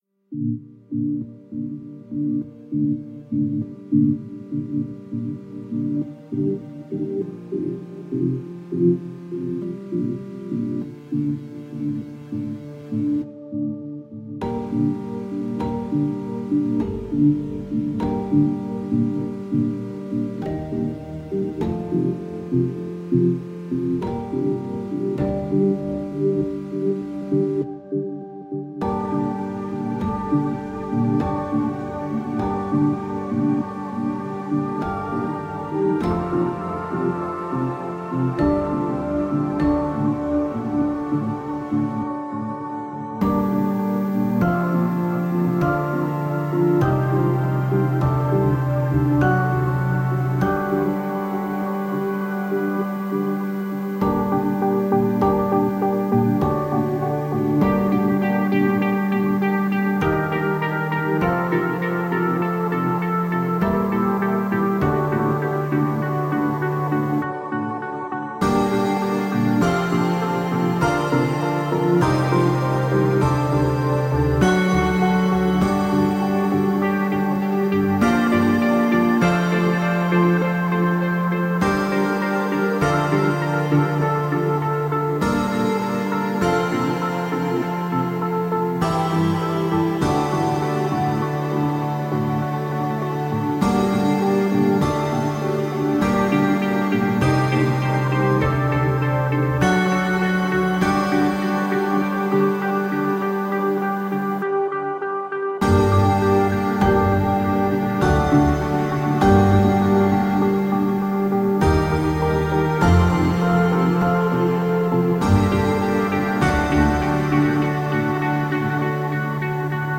Diverse, intelligent electronica from london.